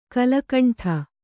♪ kalakaṇṭha